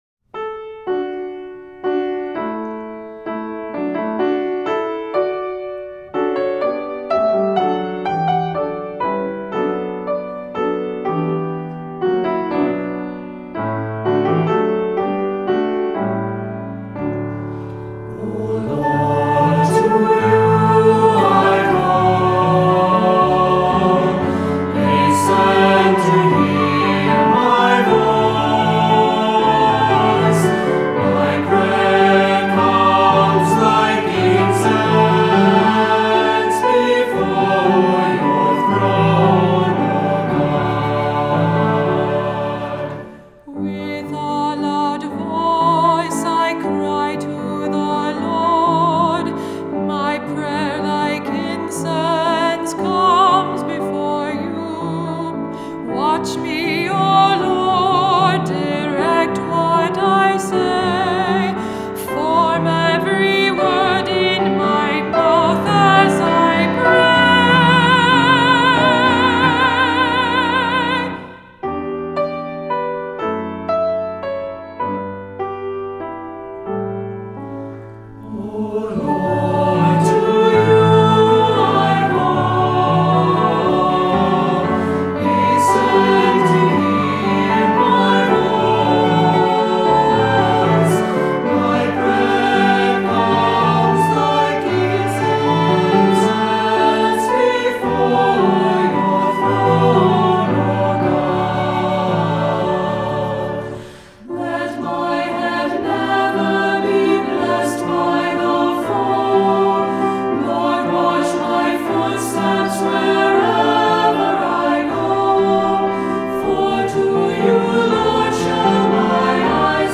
Voicing: Cantor, assembly, descant